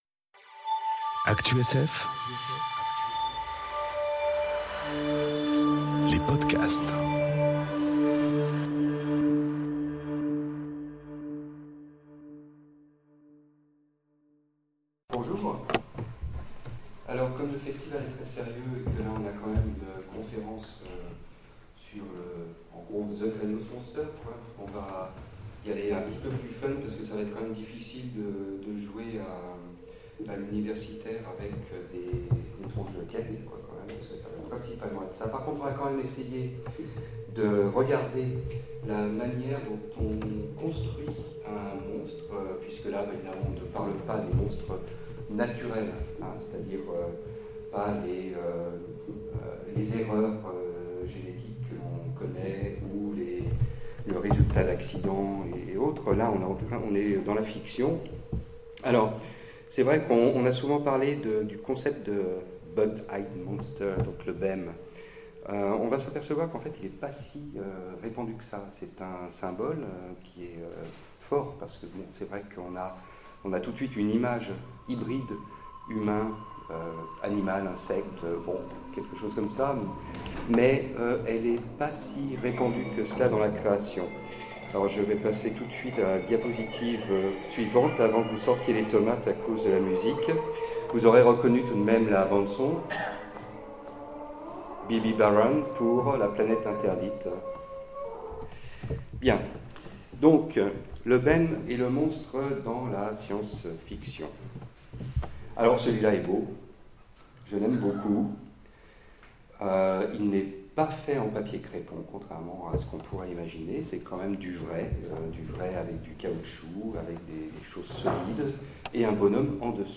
Conférence Le corps du bug-eyed monster en science-fiction enregistrée aux Utopiales 2018